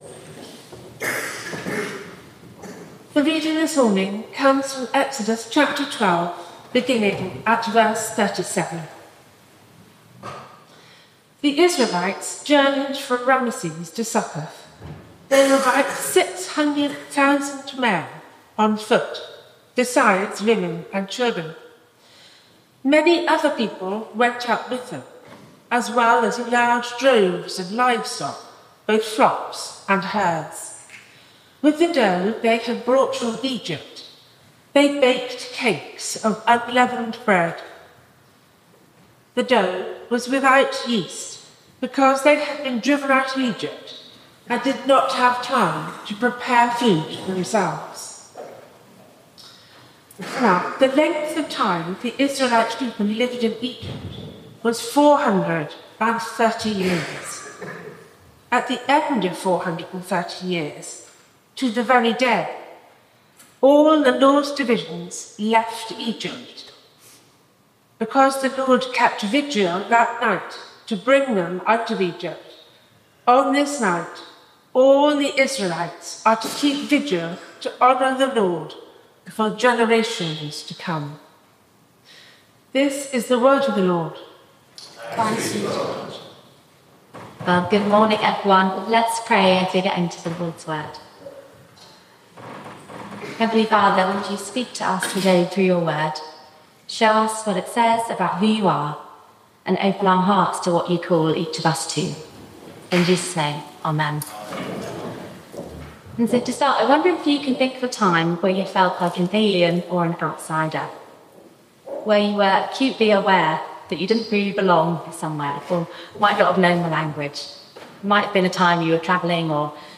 Service Type: Communion